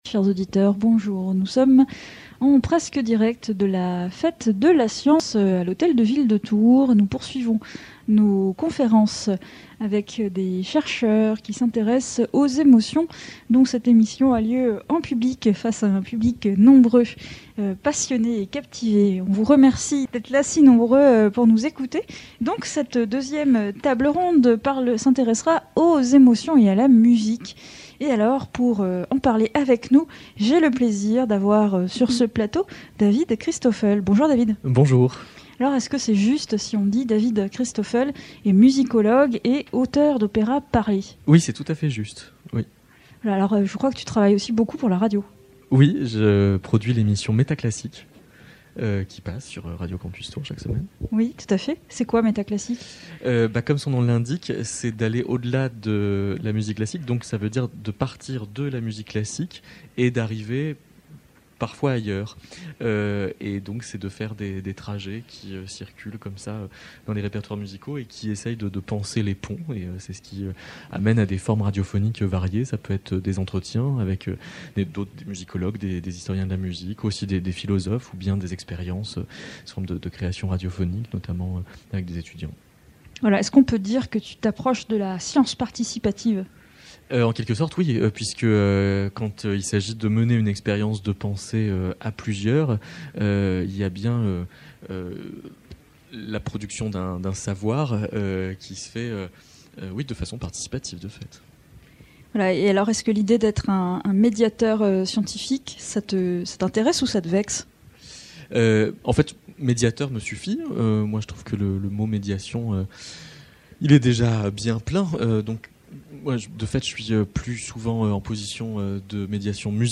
Table ronde enregistrée samedi 2 octobre 2021 à l’Hôtel de Ville de Tours.